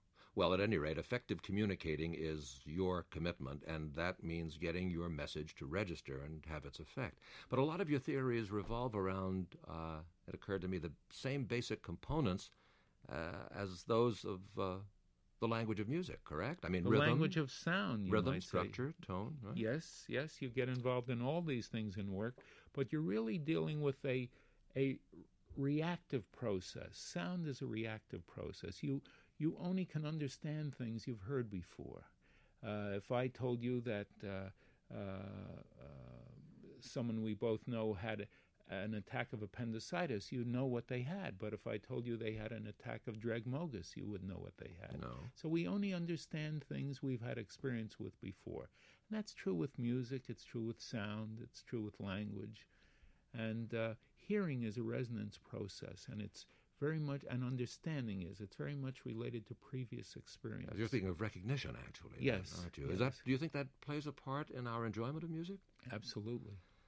In another interview, Schwartz explains his approach to sound, recognition, and how he exploits our need to understand the unfamiliar through the familiar:
WQXR_Schwartz_interview.mp3